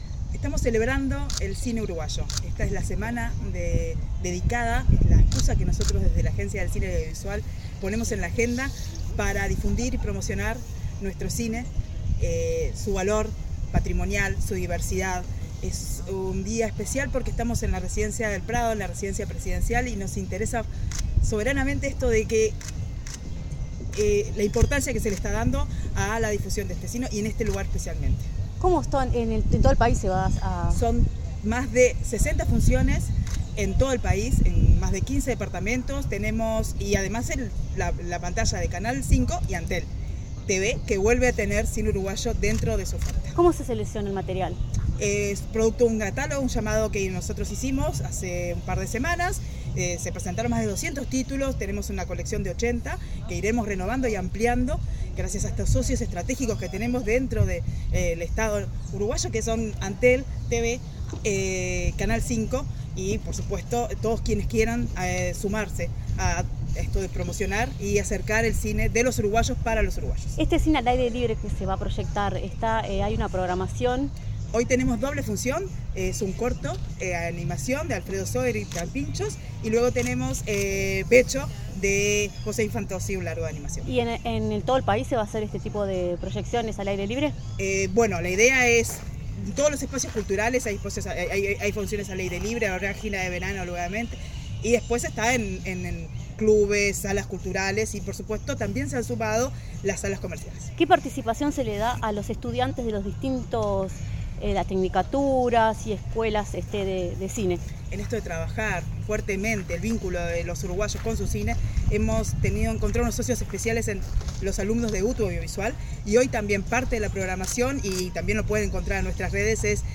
Declaraciones de la presidenta de ACAU, Gisella Previtali